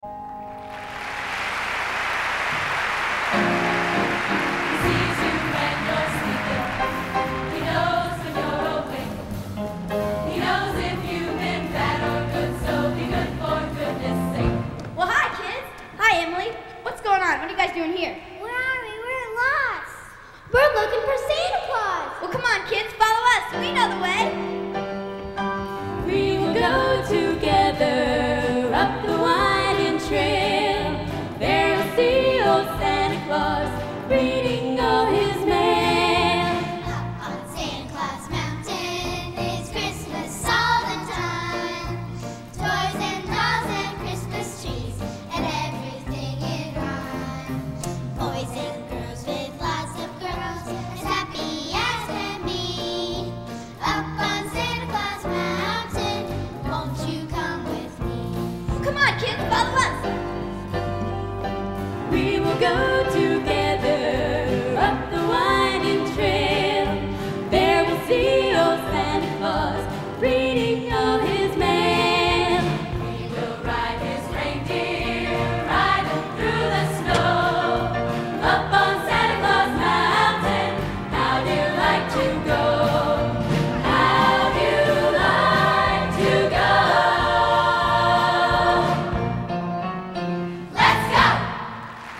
Collection: Christmas Show 1994
Location: West Lafayette, Indiana
Genre: | Type: Christmas Show |